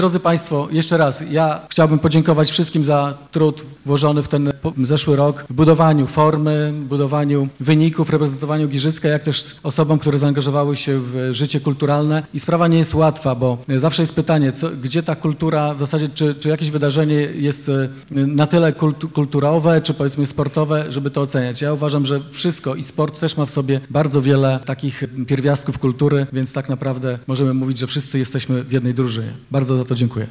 Podczas piątkowej uroczystości uhonorowano ponad setkę działaczy kultury, sportowców i trenerów.
– Jesteśmy jedną drużyną – mówił burmistrz Giżycka Wojciech Iwaszkiewicz.